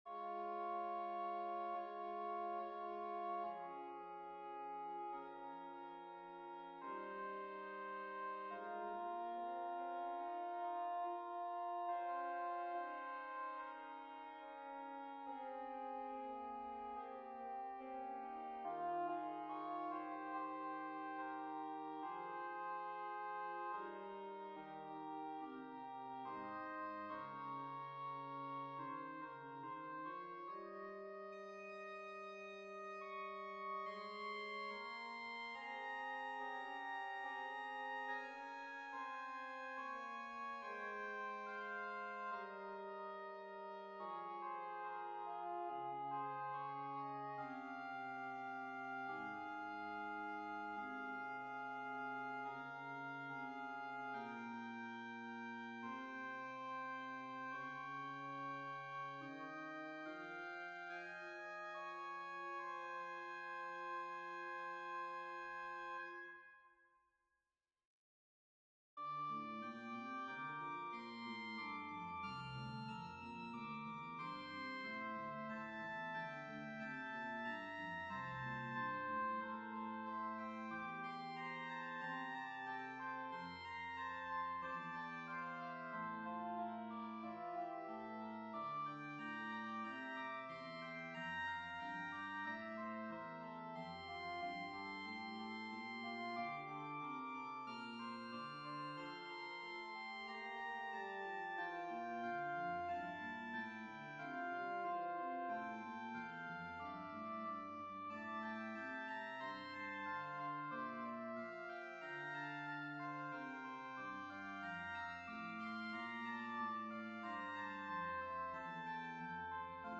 Een simpele prelude met een dynamisch tweede fuga voor een klein blazerstrio.